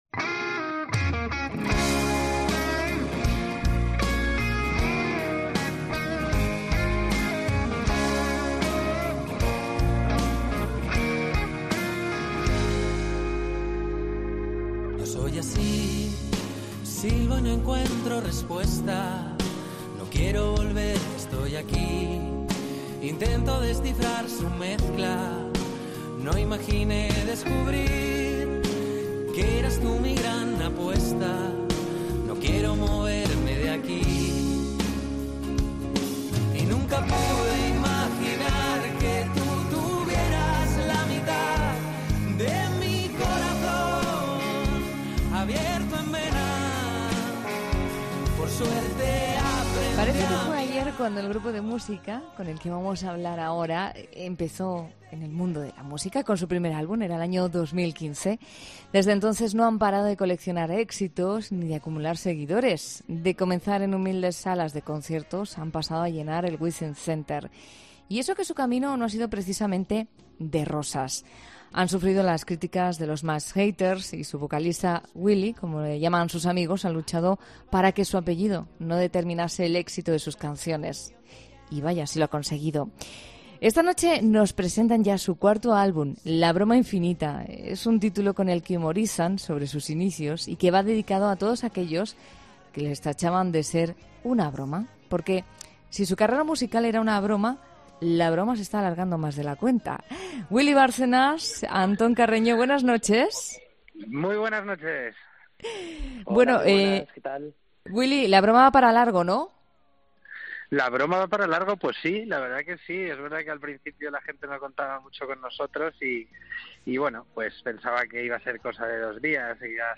Parece que fue ayer cuando el grupo de música que te presentamos en 'La Noche' de COPE lanzó su primer álbum en 2015.